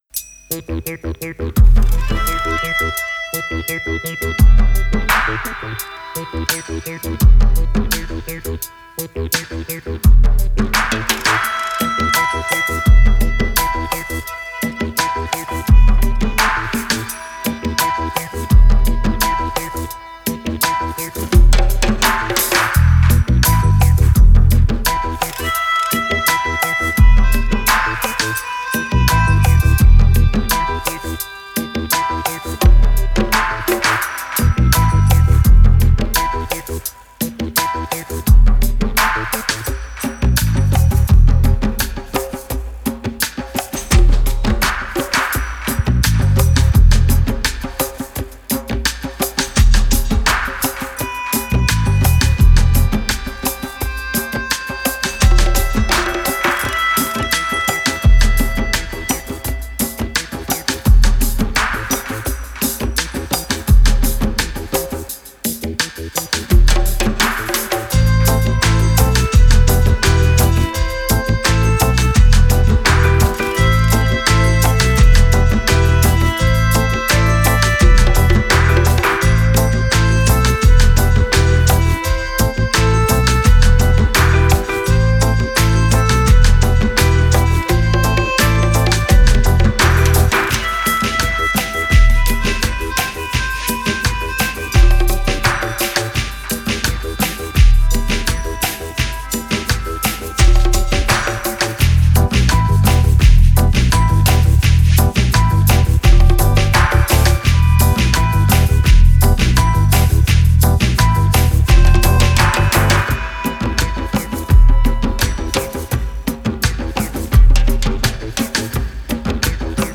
Genre: Dub, Psy-Dub.